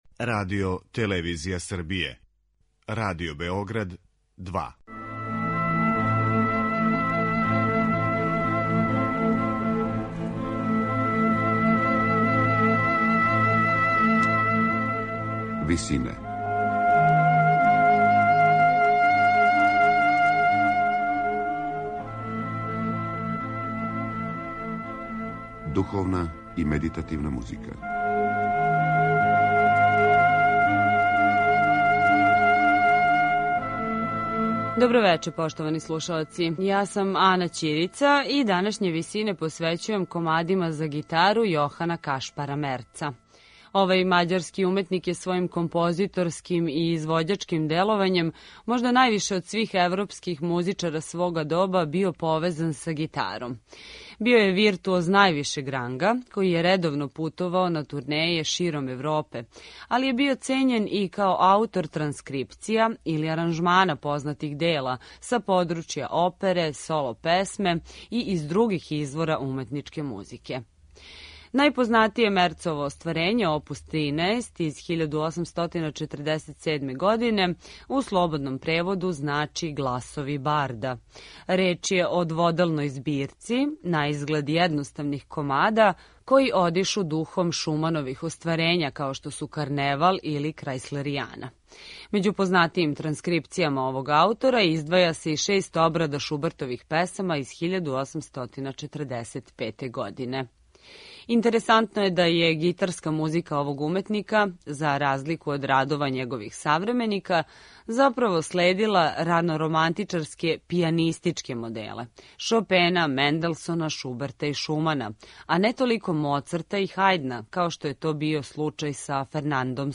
Музика за гитару